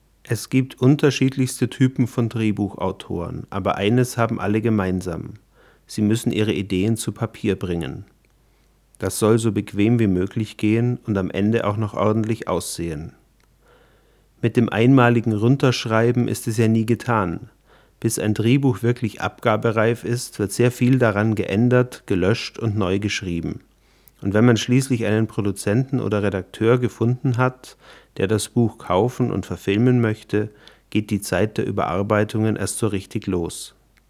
Sprachaufnahmen - Bitte um Beurteilung
Alle wurden hier in meinem Wohnzimmer (Dachwohnung mit Teppichboden) aus ca. 20 cm Abstand gemacht und anschließend in Audacity normalisiert; weitere Bearbeitung steckt noch nicht drin.